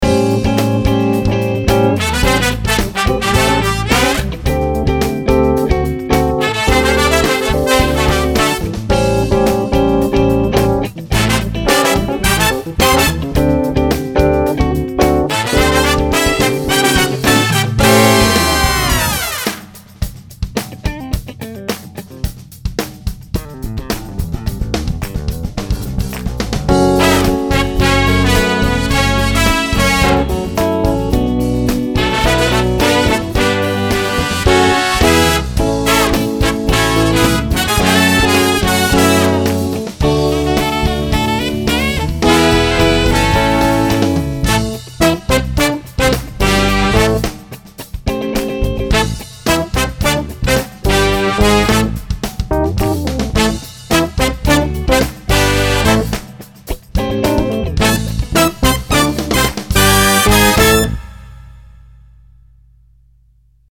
For now, here are some rough mix samples.